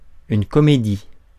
Ääntäminen
Ääntäminen US Tuntematon aksentti: IPA : /ˈæktɪŋ/ Lyhenteet ja supistumat act.